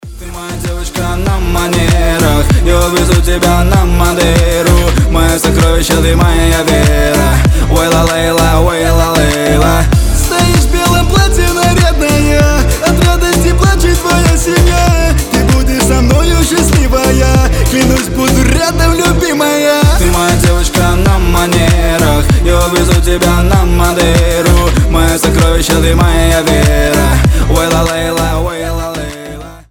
• Качество: 320, Stereo
заводные